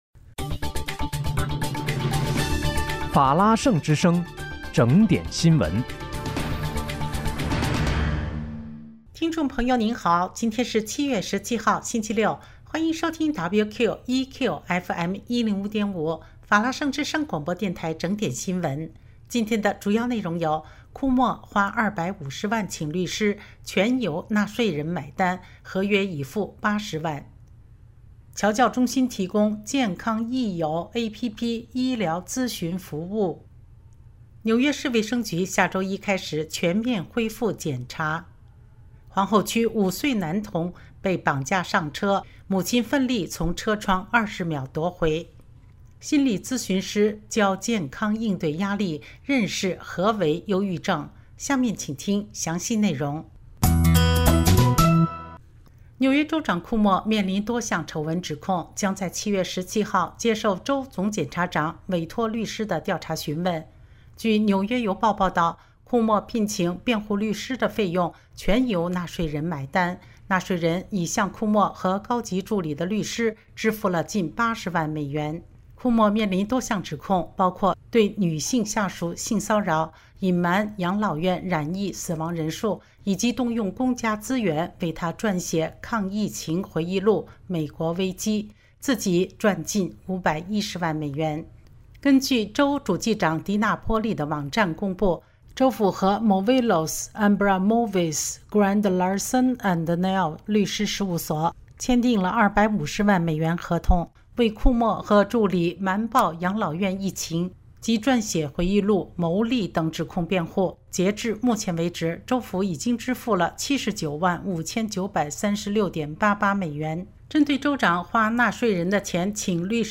7月17日（星期六）纽约整点新闻